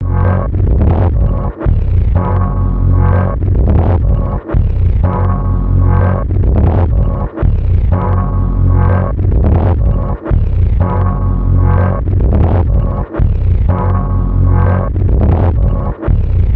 金属打击乐的卷对卷磁带循环录音 " Swing Guang
描述：扭曲的金属混响与磁带操纵引起的惊叹，在1/4"磁带上录制，物理循环
Tag: 金属混响 切好的 失真 带环 带操控